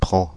Ääntäminen
Ääntäminen France (Île-de-France): IPA: /pʁɑ̃/ Paris: IPA: [pʁɑ̃] Haettu sana löytyi näillä lähdekielillä: ranska Käännöksiä ei löytynyt valitulle kohdekielelle.